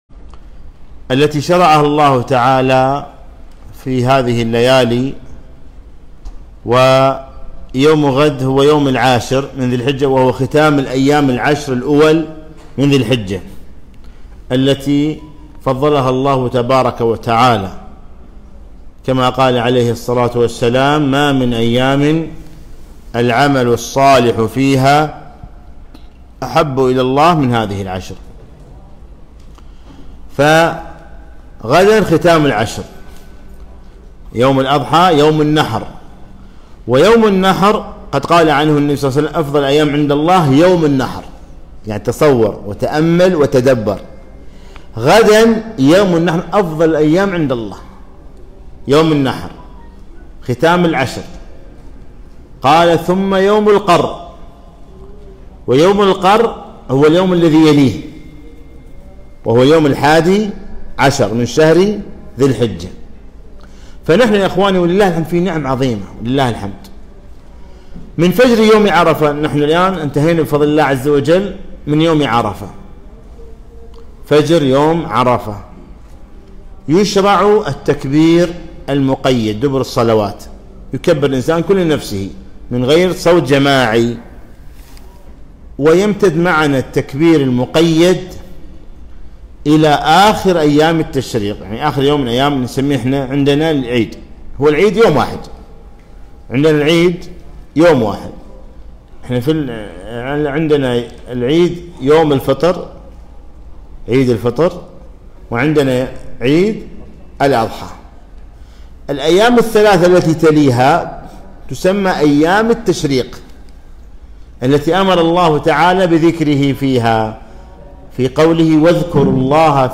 محاضرة - عيد الأضحى سنن وأحكام